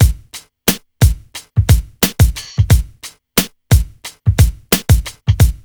RNB89BEAT1-L.wav